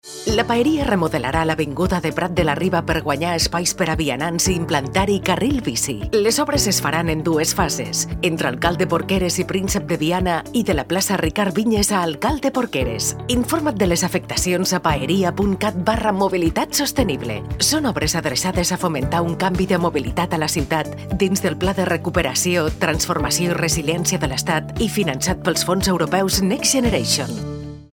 Primera falca radiofònica: